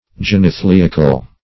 Genethliacal \Gen`eth*li"a*cal\, a.